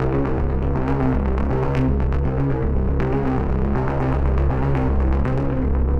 Index of /musicradar/dystopian-drone-samples/Droney Arps/120bpm
DD_DroneyArp3_120-C.wav